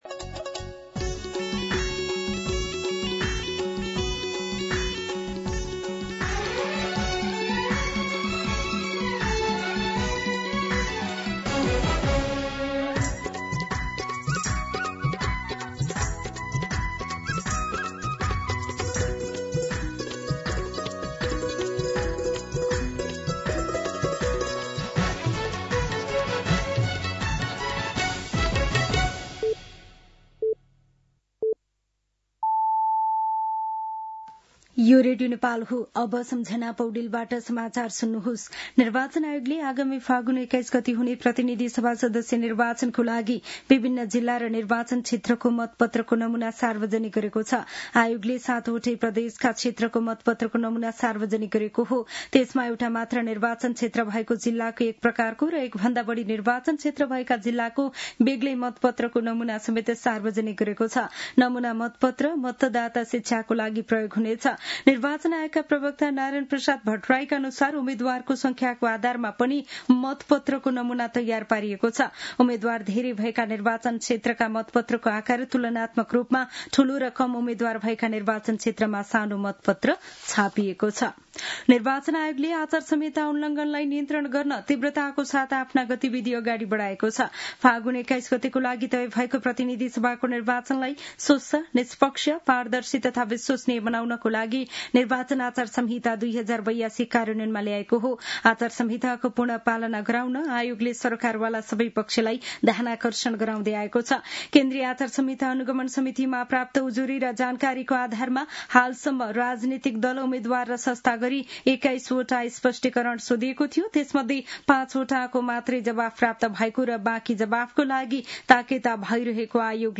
दिउँसो ४ बजेको नेपाली समाचार : १७ माघ , २०८२
4-pm-News-10-17.mp3